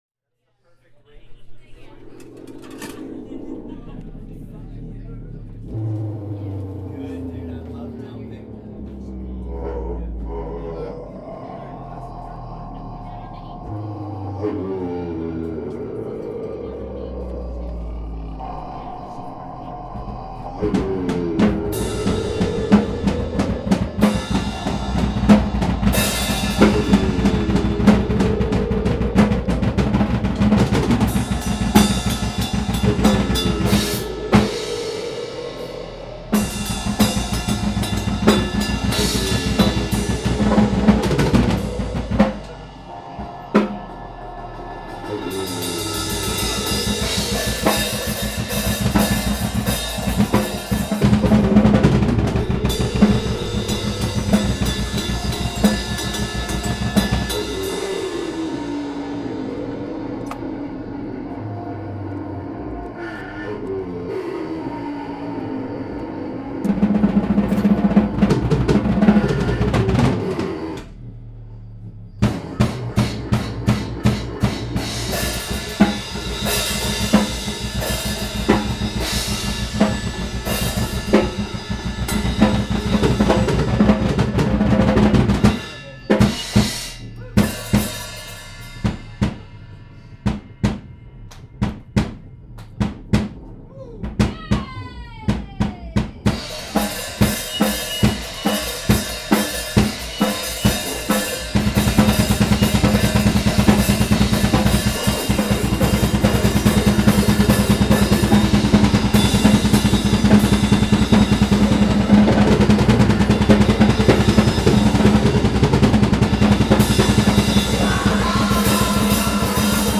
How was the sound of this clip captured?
live show...